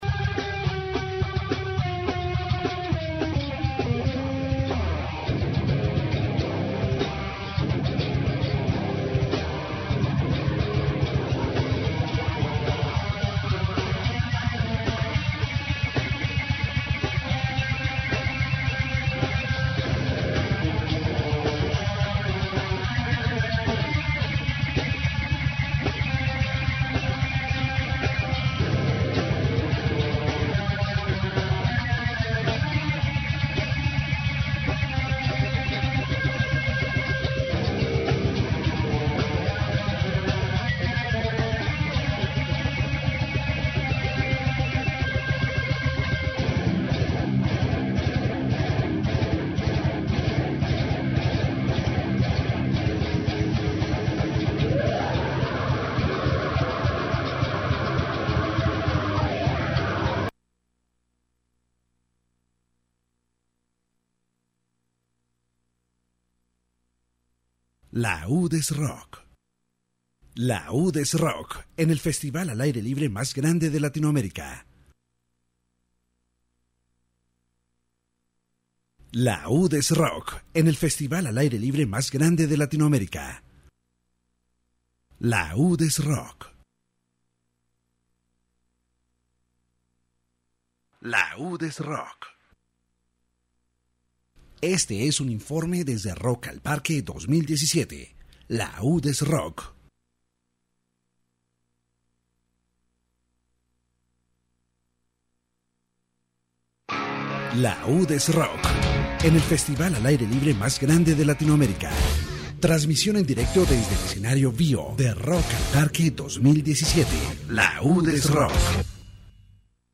The radio report from Rock al Parque 2017 covers live broadcasts from the Bio stage during the festival’s first day, known as Metal Day.